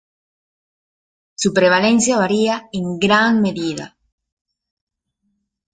Pronúnciase como (IPA) /pɾebaˈlenθja/